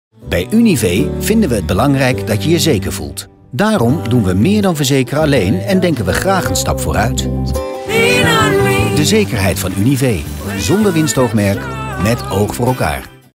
Voice Demos